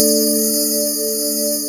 PADY CHORD03.WAV